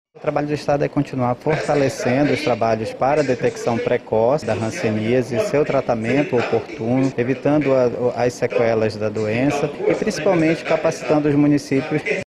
Para frear a doença, a instituição convocou equipes do estado e município para uma ação conjunta no tratamento e prevenção, como explica Lindinaldo Santos que é Secretário Adjunto de Políticas de Saúde da SES.